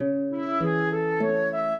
flute-harp
minuet5-5.wav